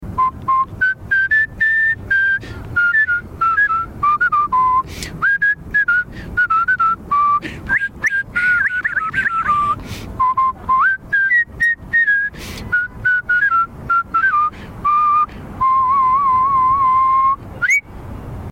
He varies the whistling using different techniques, such as a warble, slur or vibrato, combining them to make the music more interesting.
Professional whistler